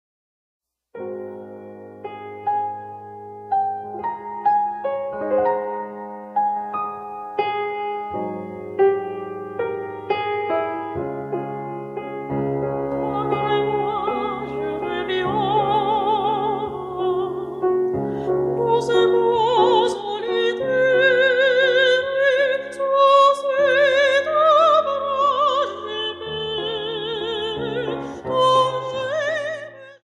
piano.
Grabado: Estudio B, IMER, 1996, Piano Steinway